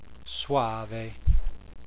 Italian Wine Pronunciation Guide
Click on a speaker symbol to hear the word spoken aloud.